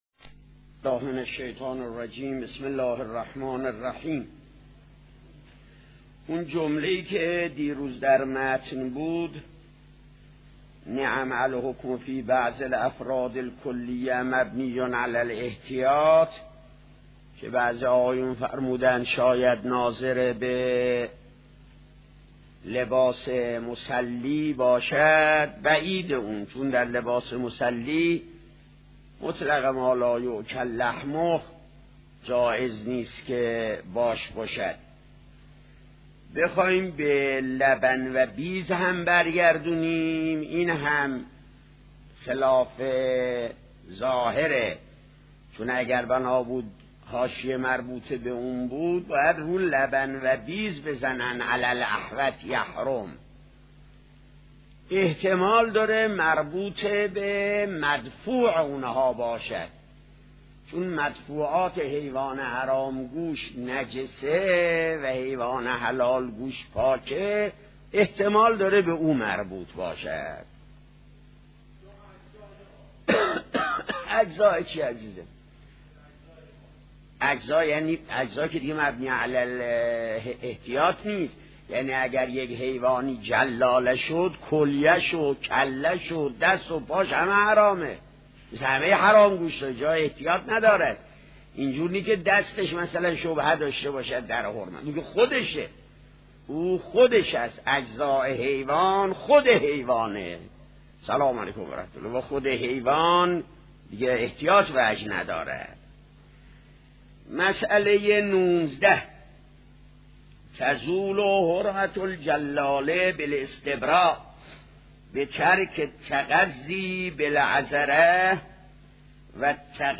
آيت الله صانعي - خارج اصول | مرجع دانلود دروس صوتی حوزه علمیه دفتر تبلیغات اسلامی قم- بیان